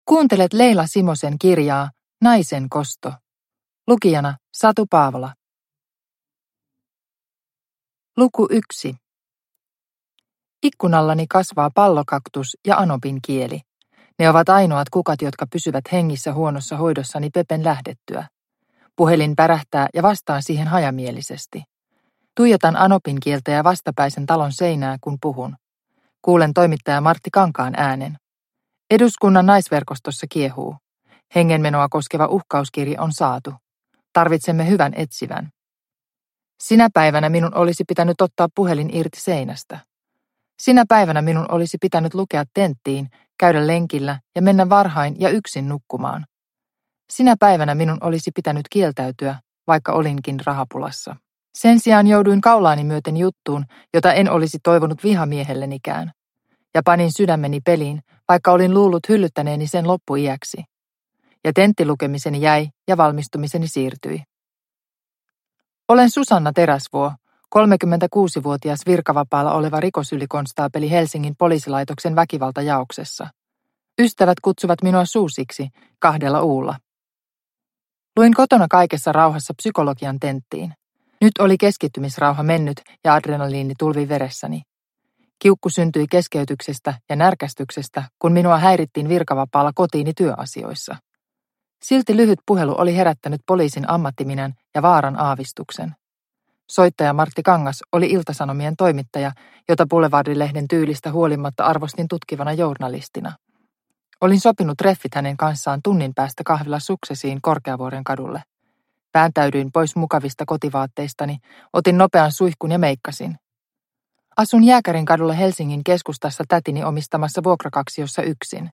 Naisen kosto – Ljudbok – Laddas ner